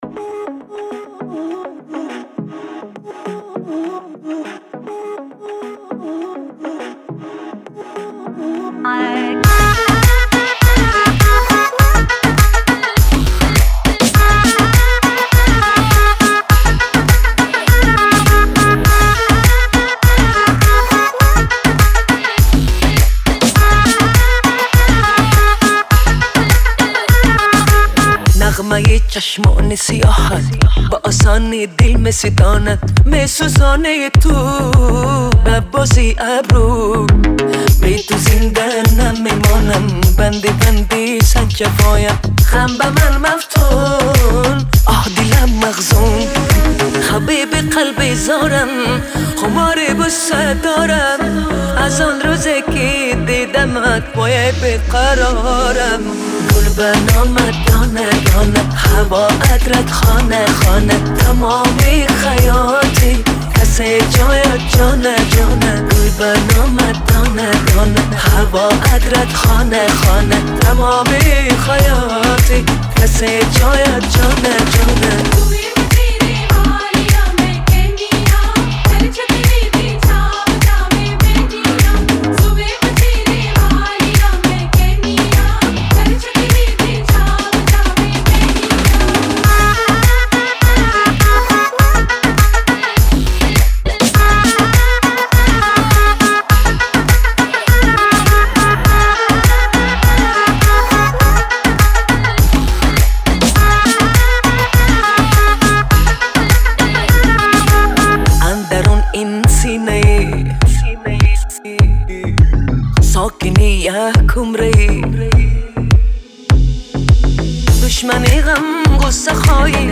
яркая и мелодичная песня